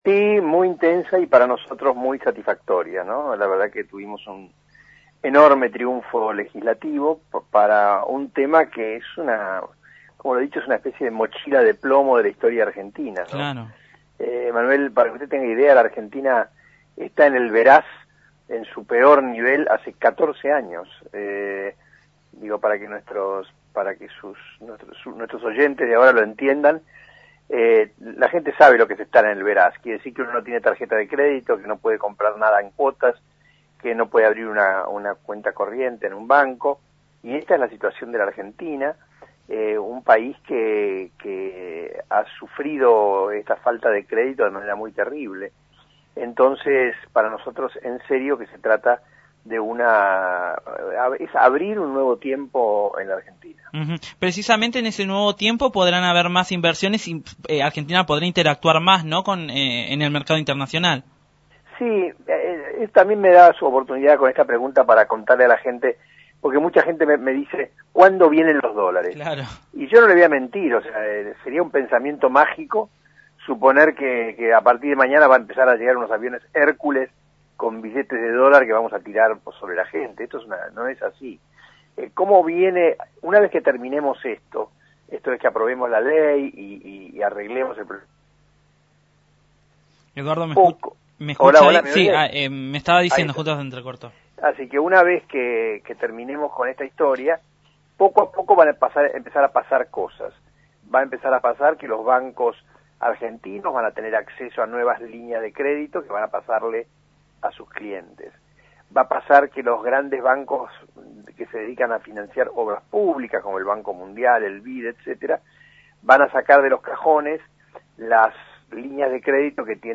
Eduardo Amadeo, Diputado Nacional de Cambiemos, habló en Nube Sonora luego de que se le diera la media sanción en Diputados el endeudamiento que permite avanzar en el acuerdo con los Fondos Buitre, para enviar la discusión en Senadores.